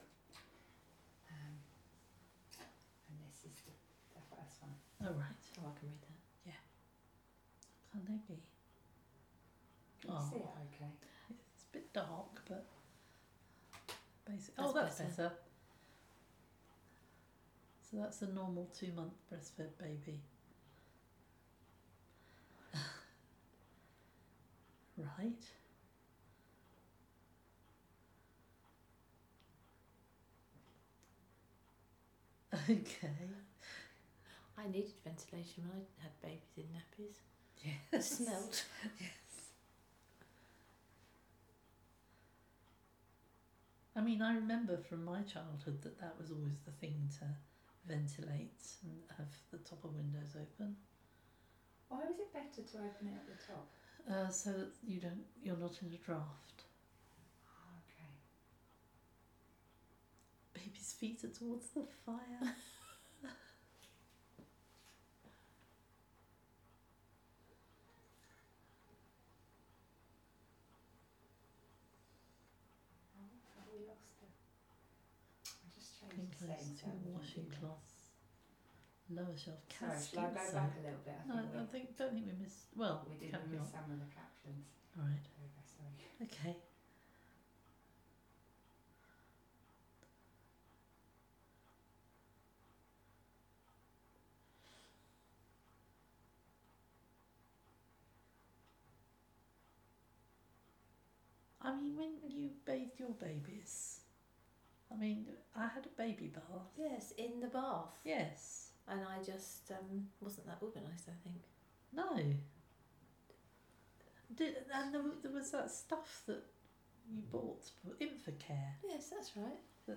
An interview featuring two women and the recordist responding and reacting to the silent film, Bathing & Dressing, Parts 1 & 2. There is some candid discussion about ideas for the final soundtrack towards the end of the conversation.